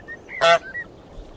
Honk2
Category: Sound FX   Right: Personal
Tags: goose